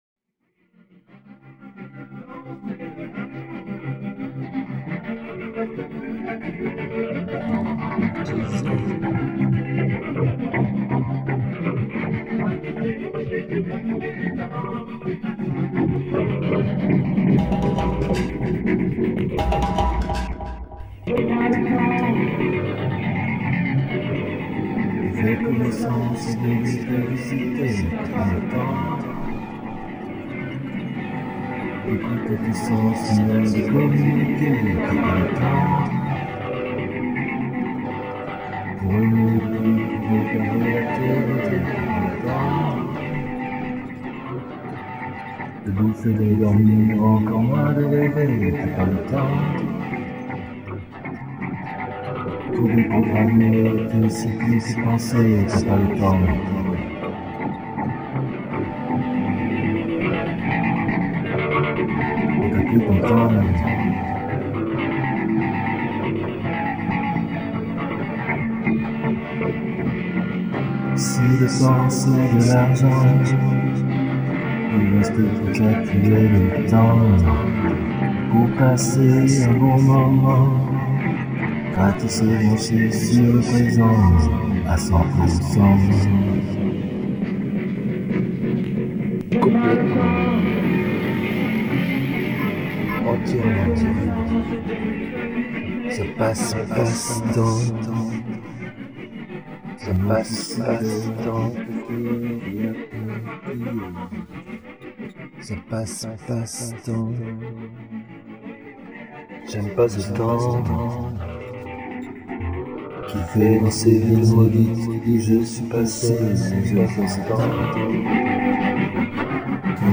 maquette v. électroniquée